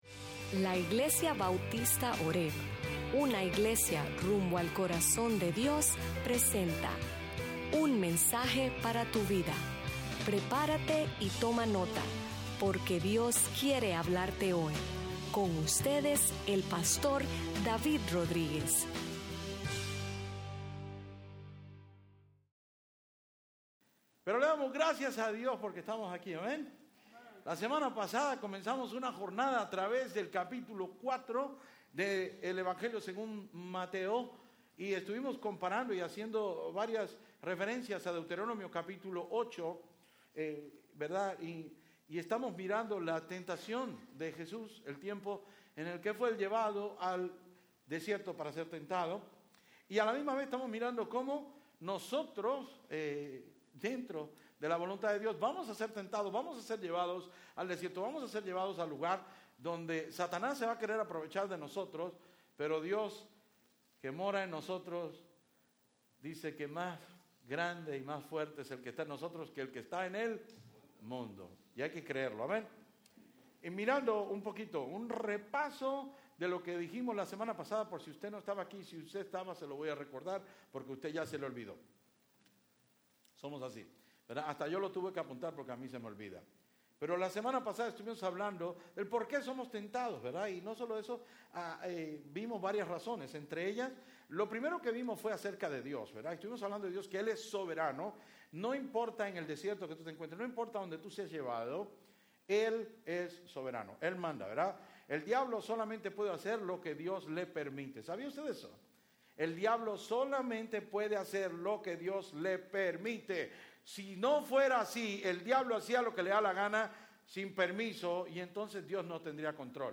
Sermons Archive - Page 93 of 156 - horebnola-New Orleans, LA